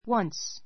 wʌ́ns ワ ン ス